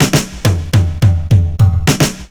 FILL 7    -R.wav